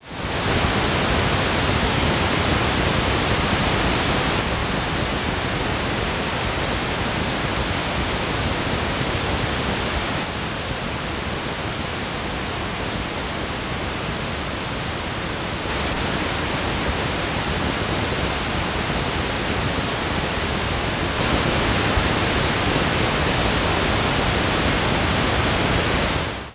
(28s) N Relative SPL 3dB Hi | Low Pink noise @ varied 3dB increments.